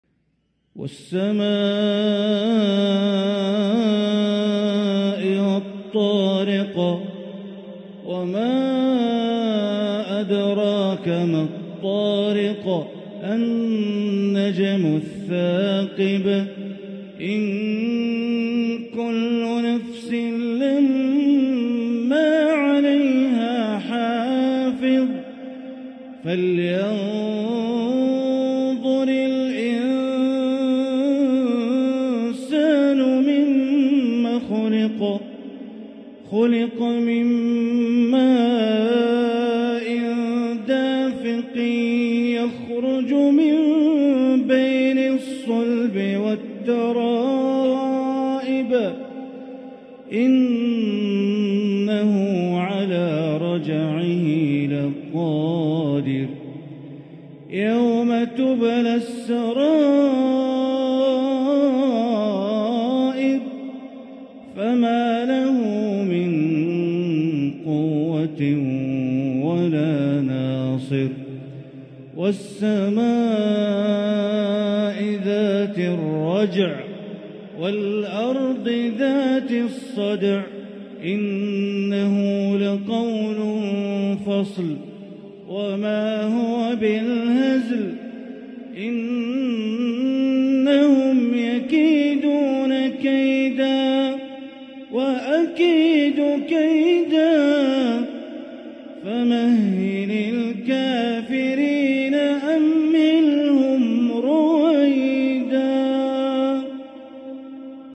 سورة الطارق > مصحف الحرم المكي > المصحف - تلاوات بندر بليلة